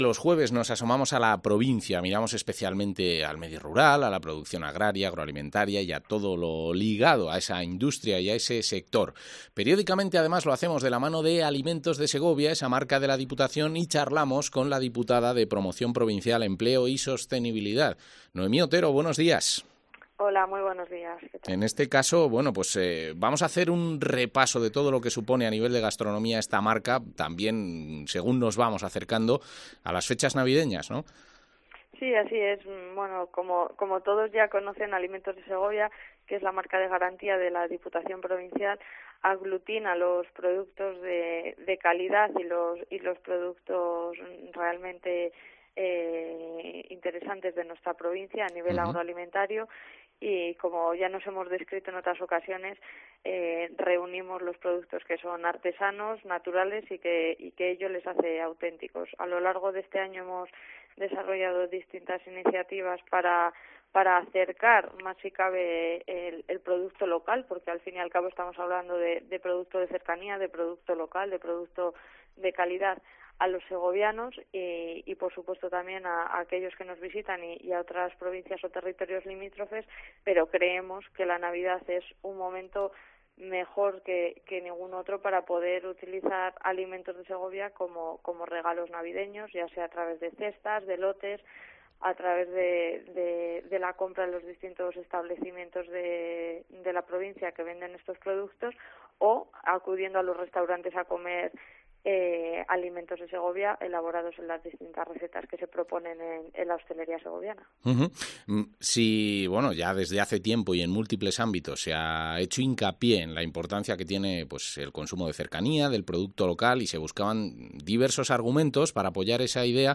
Entrevista a la diputada de Promoción Provincial, Empleo y Sostenibilidad, Noemí Otero